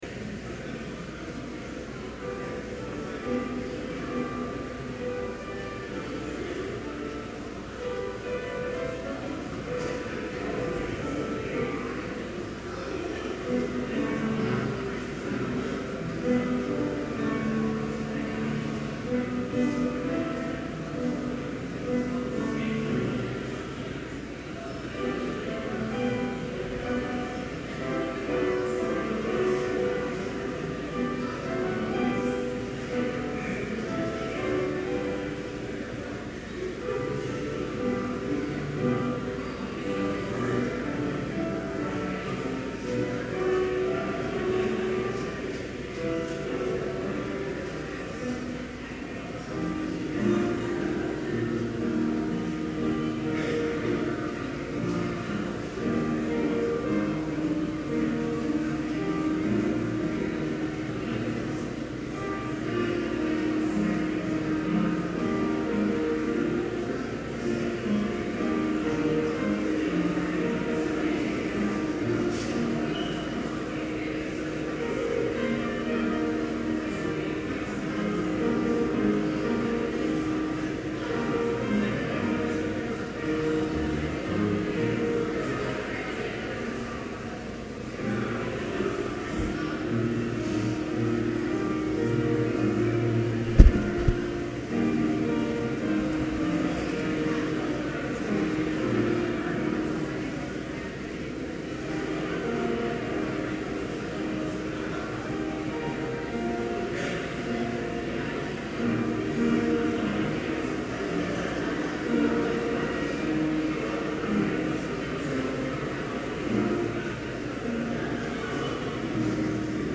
Complete service audio for Chapel - October 12, 2011